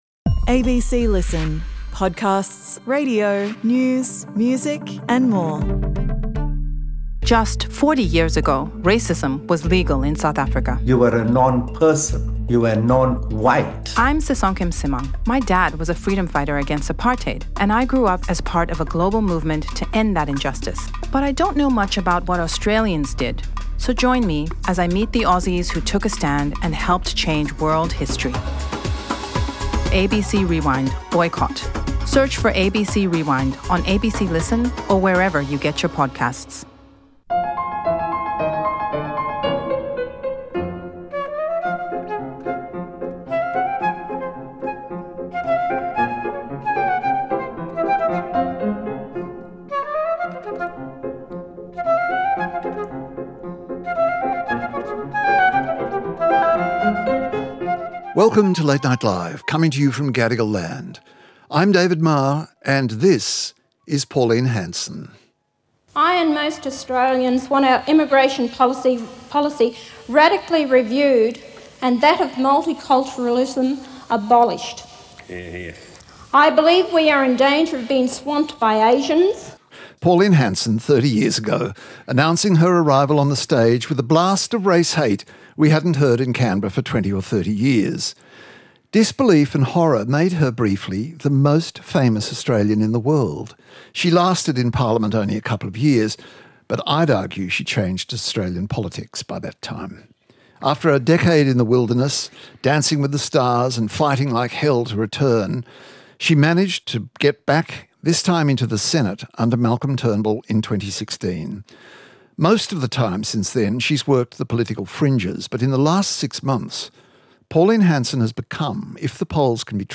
Guests: Niki Savva, journalist, author and former political advisor to Peter Costello Paul Kelly, Editor-at-large for The Australian
ABC Election Analyst Emeritus, Antony Green